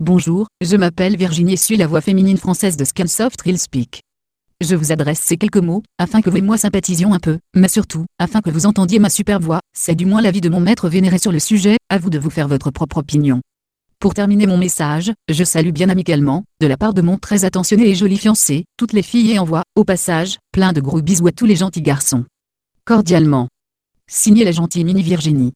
Texte de démonstration lu par Virginie (Nuance RealSpeak; distribué sur le site de Nextup Technology; femme; français)
Écouter la démonstration de Virginie (Nuance RealSpeak; distribué sur le site de Nextup Technology; femme; français)